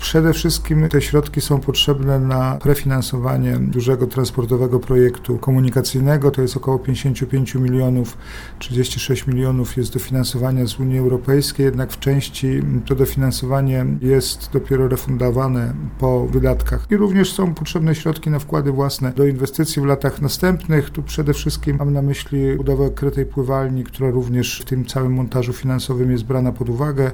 Realizujemy inwestycje, które będą dofinansowane, ale wcześniej trzeba mieć swoje środki – powiedział Jacek Milewski wiceprezydent Nowej Soli